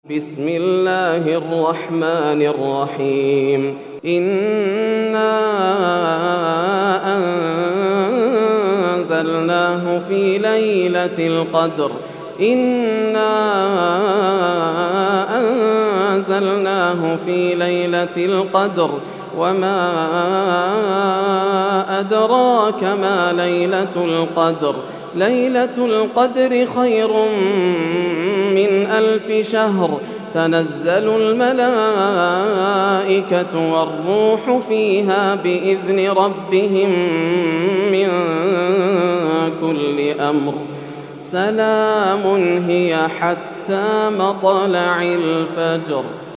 سورة القدر > السور المكتملة > رمضان 1431هـ > التراويح - تلاوات ياسر الدوسري